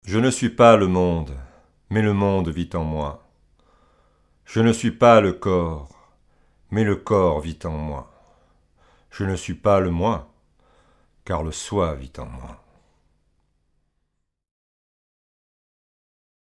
Enregistrement numérique